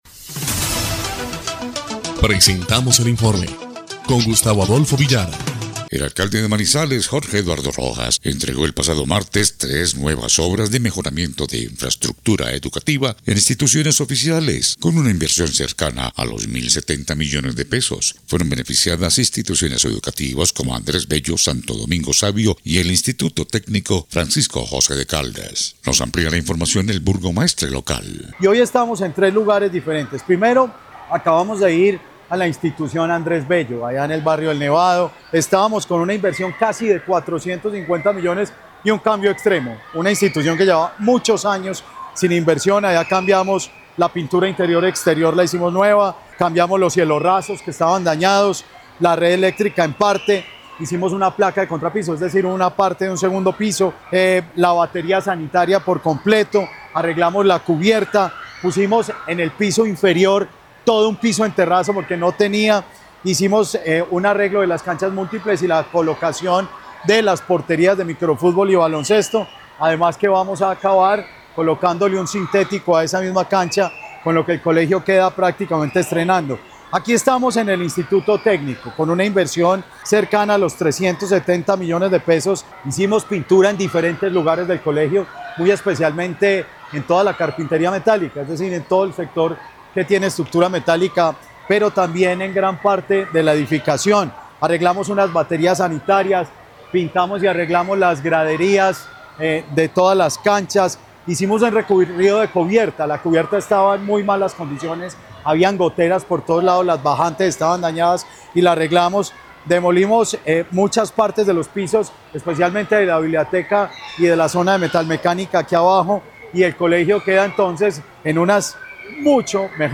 EL INFORME 3° Clip de Noticias del 28 de enero de 2026